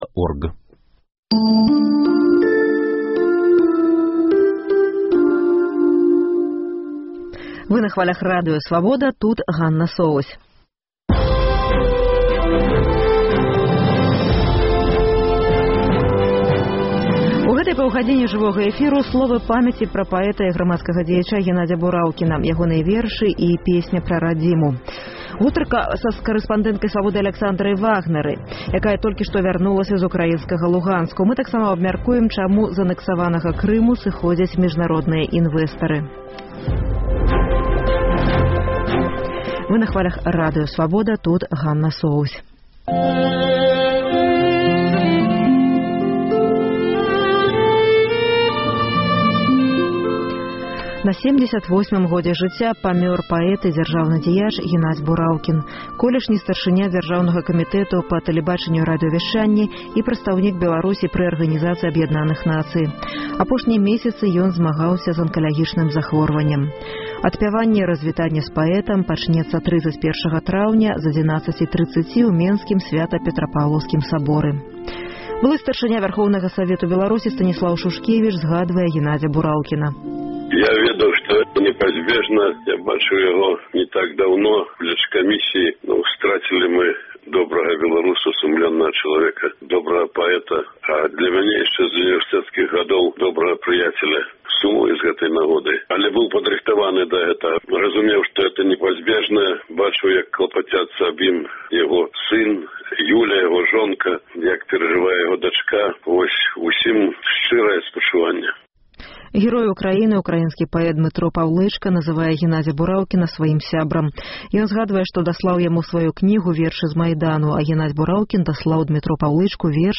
Сёньня ў праграме Пайшоў з жыцьця паэт, дзяржаўны дзяяч Генадзь Бураўкін. У нашай праграме словы памяці, архіўныя запісы, вершы і песьні на словы Бураўкіна Журналіста Радыё Рацыя судзяць за працу без акрэдытацыі 15 гадоў трагедыі на станцыі мэтро «Няміга».